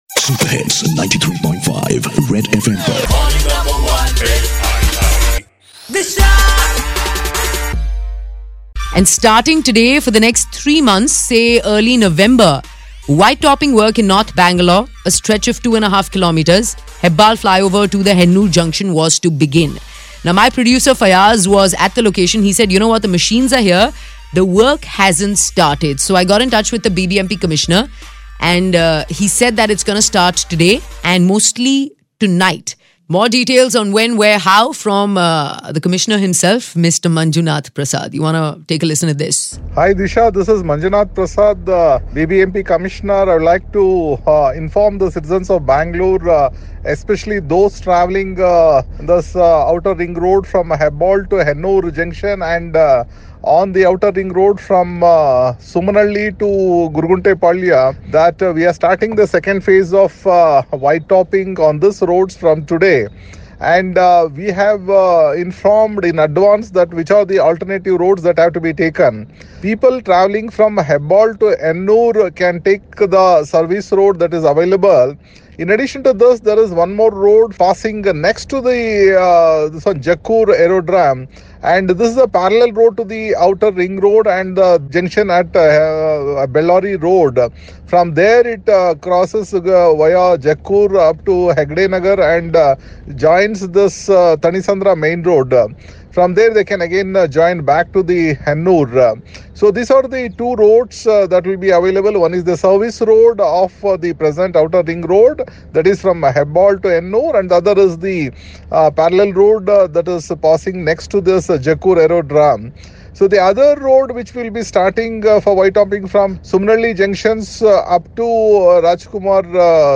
Whitetoppin work starts from today,details given by BBMP Commissioner Manjunath Prasad